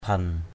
/bʱɛn/ 1.